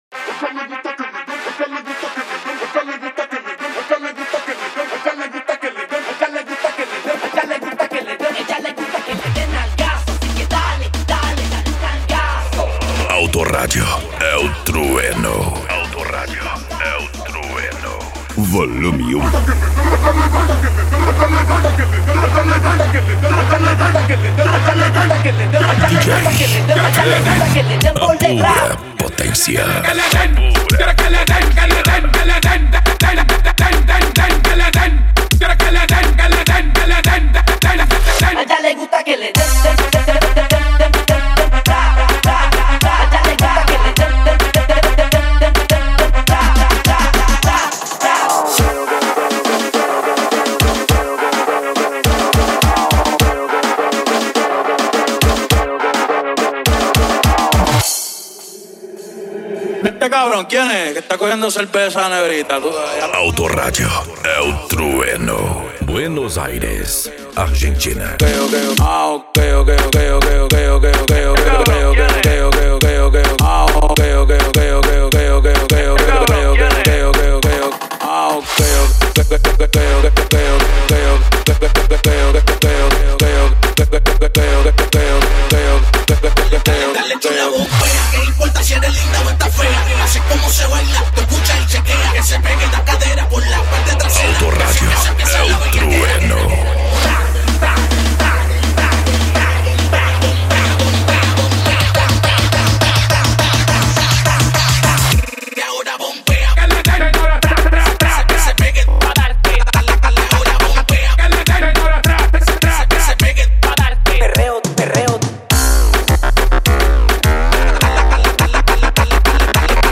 Bass
Modao
Musica Electronica
PANCADÃO
Psy Trance
Remix